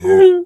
bear_pain_whimper_02.wav